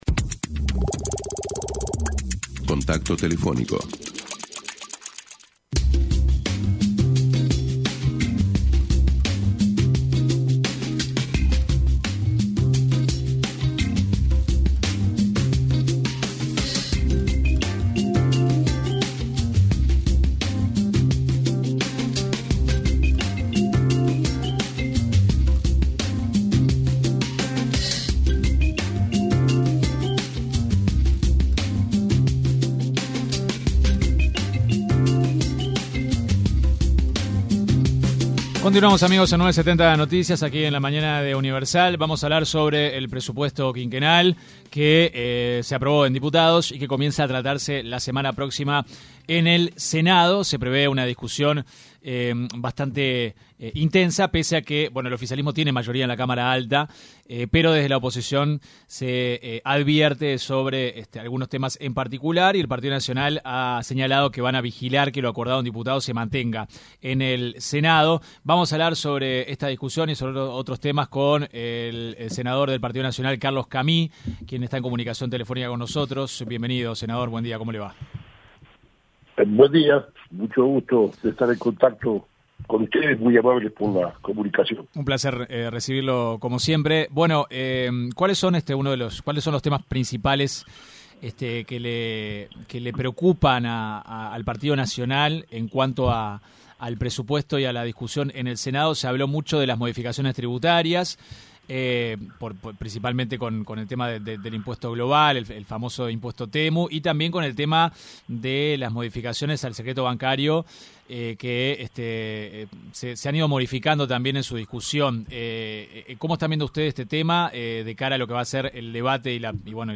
El senador del Partido Nacional, Carlos Camy, se refirió en diálogo con 970 Noticias, a la decisión del Gobierno actual, de rescindir el contrato con la empresa Cardama, a cargo de la construcción de las patrulleras oceánicas para nuestro país.